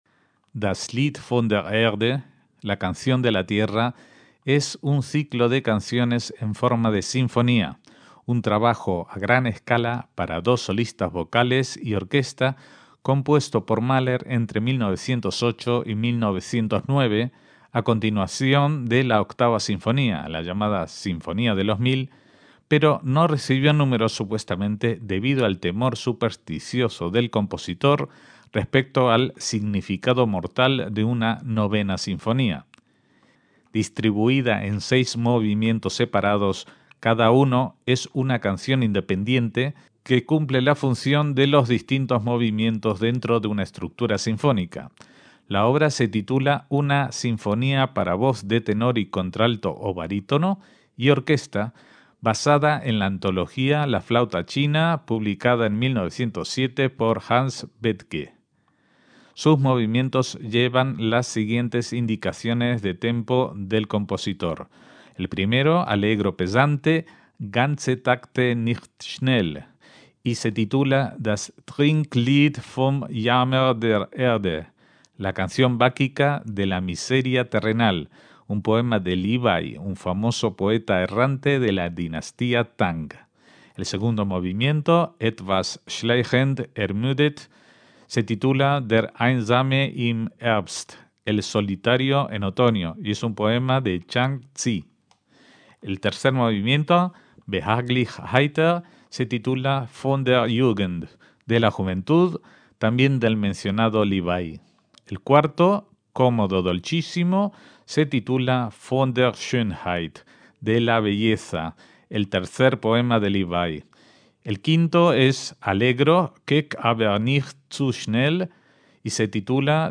“Das Lied von der Erde” (La canción de la tierra), dirigida por Lorin Maazel
un ciclo de canciones en forma de sinfonía
Se estructura en seis movimientos basados en sendos poemas de inspiración china que Mahler dotó de ciertos rasgos característicos como escalas pentatónica o de tonos enteros y la utilización de determinados instrumentos, una característica que tendrá su continuación en la Novena Sinfonía.